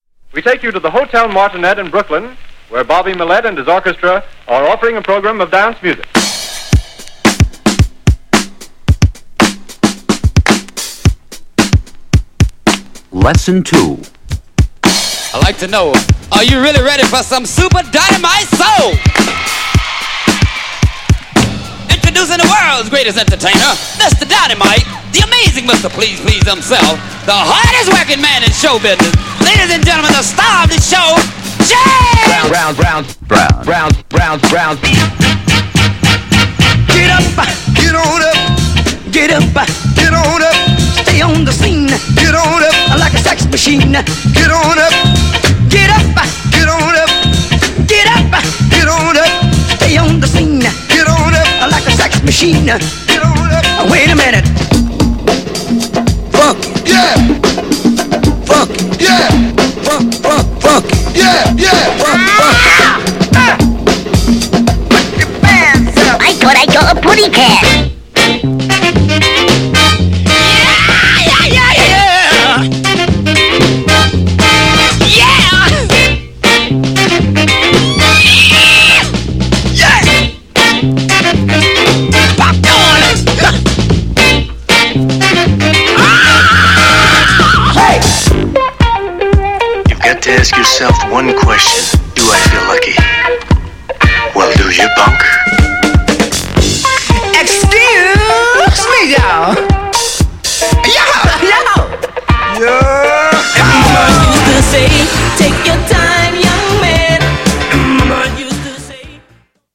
GENRE Hip Hop
BPM 116〜120BPM
アガルトラック # エレクトロ
ブレイクビーツテイスト